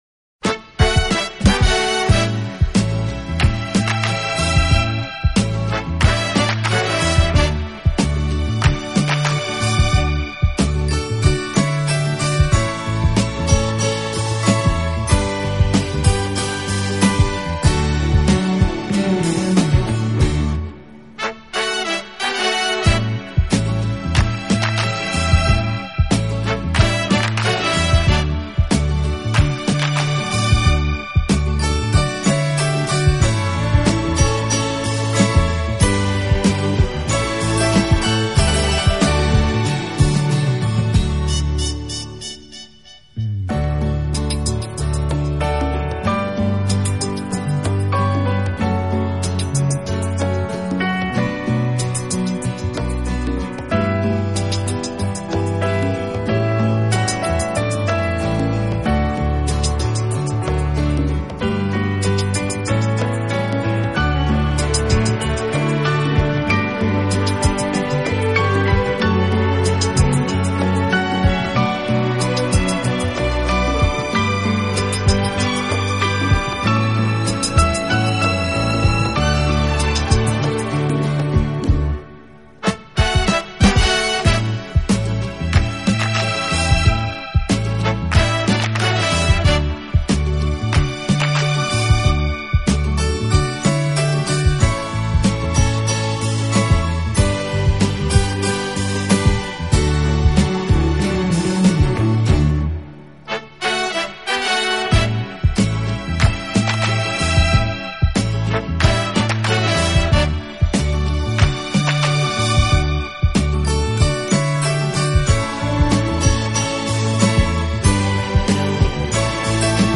“清新华丽，浪漫迷人”